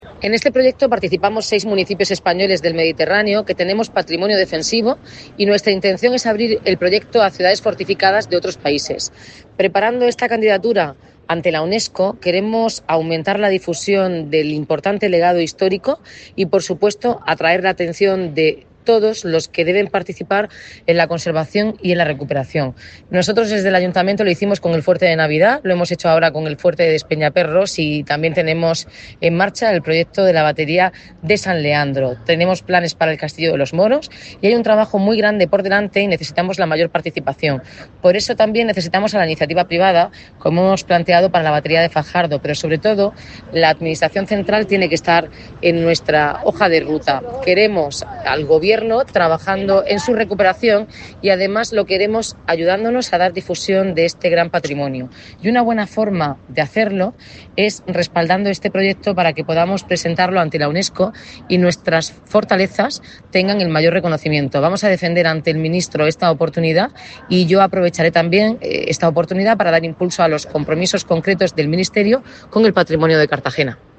Enlace a Declaraciones de Noelia Arroyo sobre el proyecto de Ciudades Fortificadas del Mediterráneo